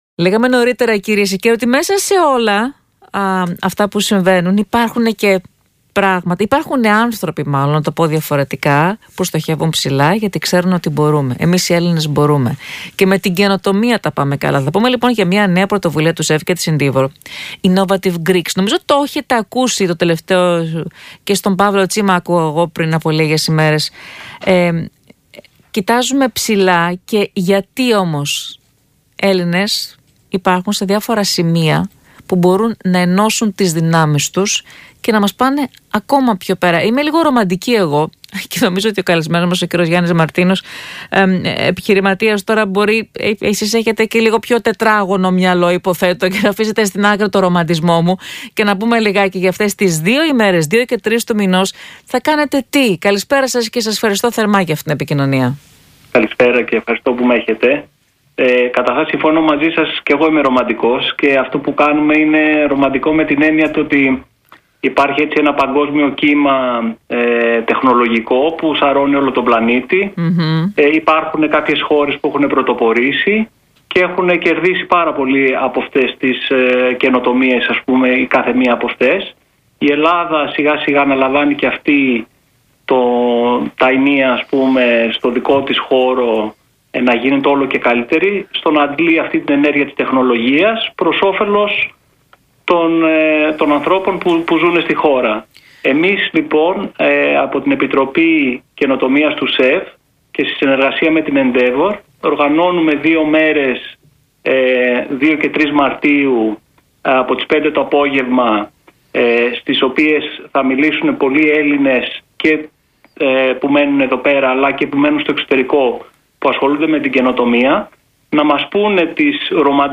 Συνέντευξη
στον Ρ/Σ ΣΚΑΪ 100.3